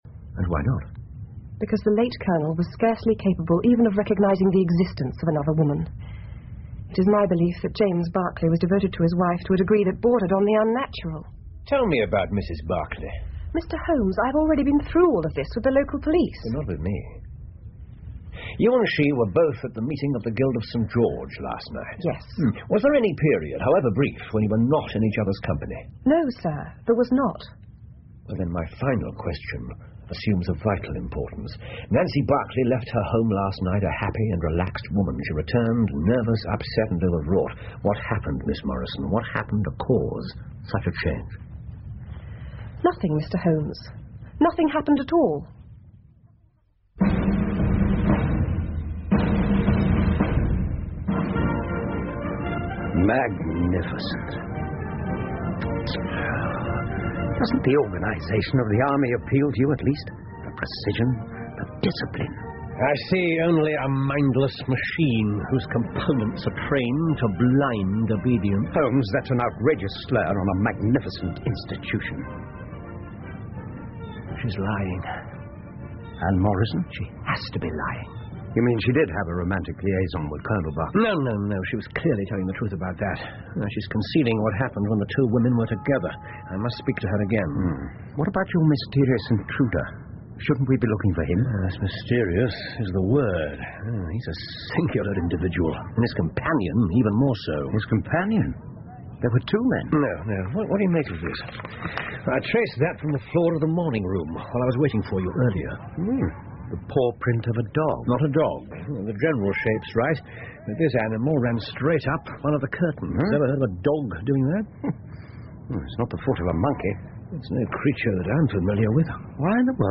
福尔摩斯广播剧 The Crooked Man 5 听力文件下载—在线英语听力室